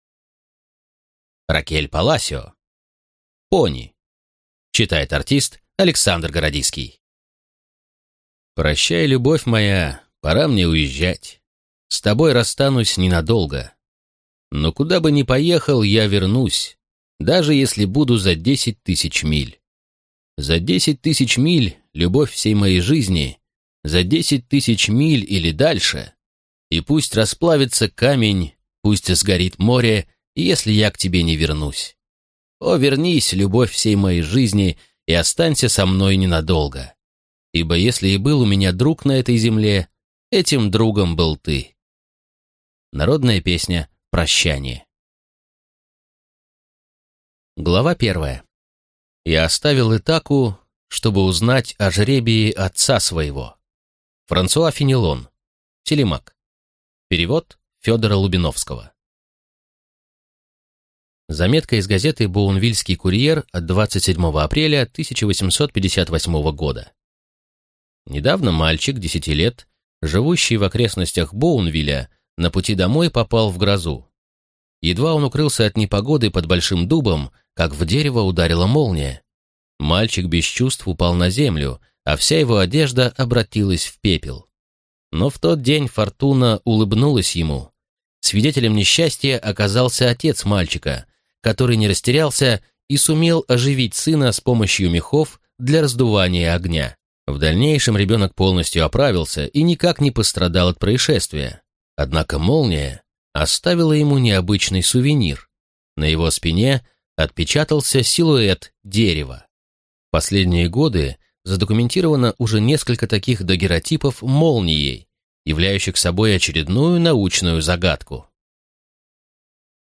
Аудиокнига Пони | Библиотека аудиокниг